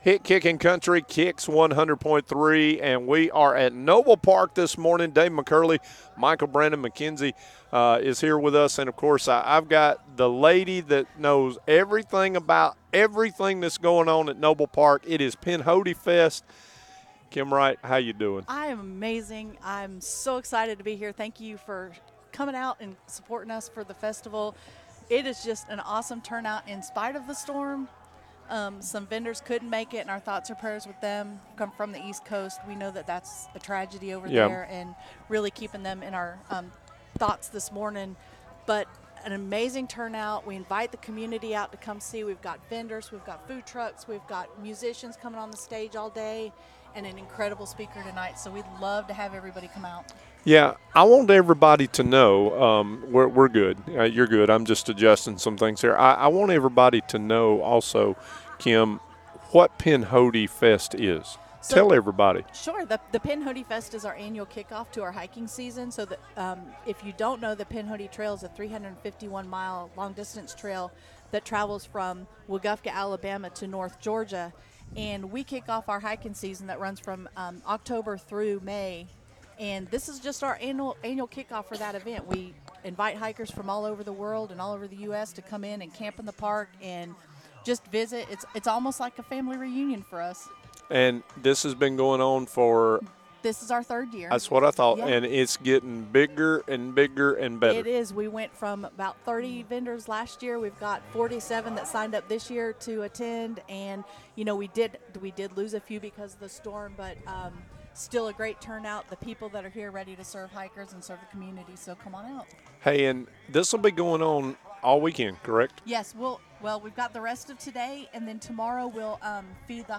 Live from Pinhoti Fest 2024